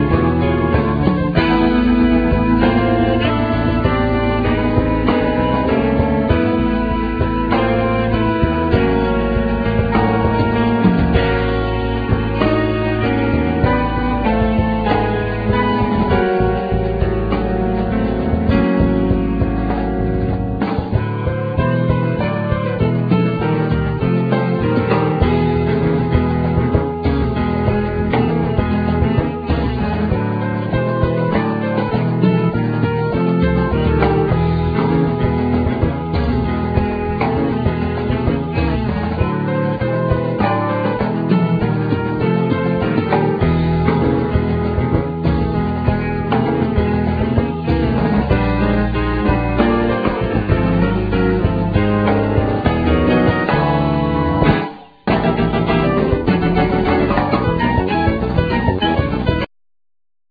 Vocal,Synthsizer,Harmonica
Drums,Keyboards
Guitar,Mandlin
Bass,Melodica
Cello,Bass